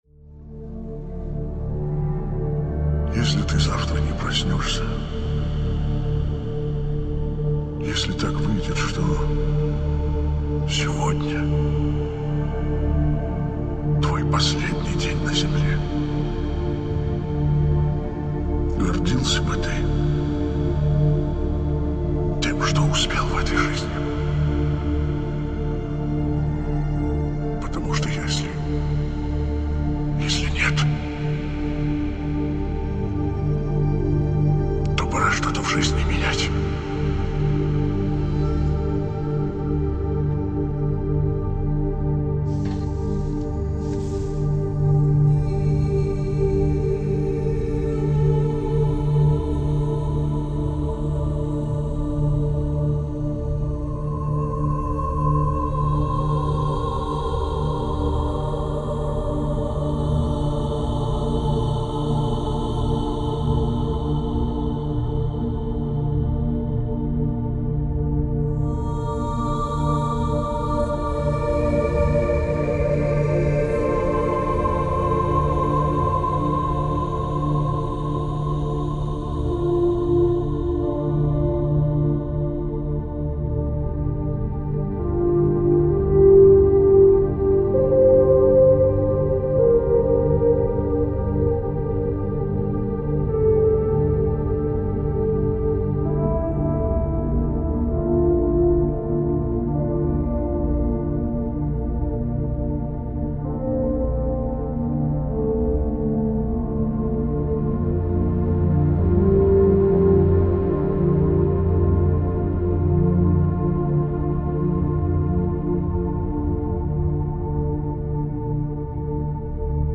Genre: Electronic SpaceSynth.